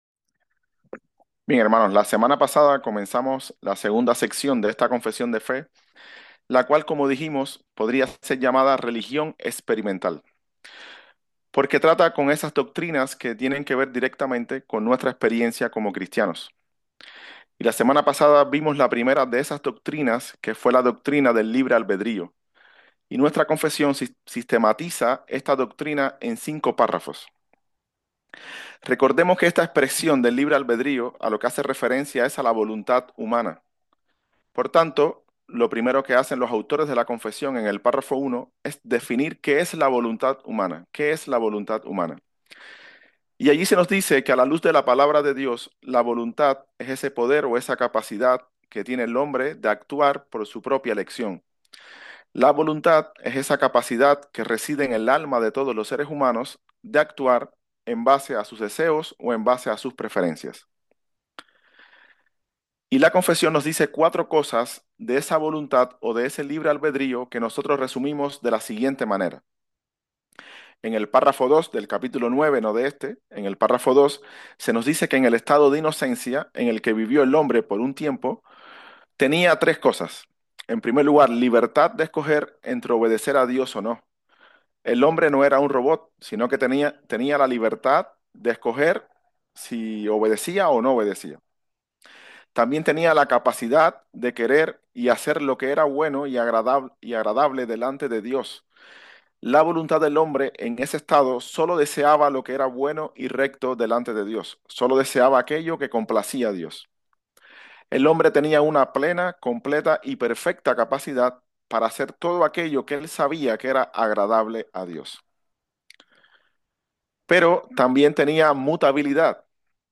SERIES DE PREDICACIONES